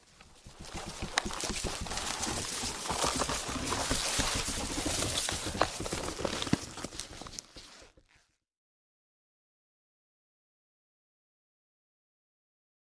Âm thanh Hồi sinh, Sức sống, Đâm chồi, Cây mọc, Năng lượng…
Thể loại: Hiệu ứng âm thanh
Description: Âm thanh hồi sinh, thanh âm phục sinh, vang vọng khởi sinh; sức sống, sinh lực, sinh khí; đâm chồi, nhú mầm, nảy lộc; cây mọc, mầm xanh, vươn lên; năng lượng, sinh năng, khí lực.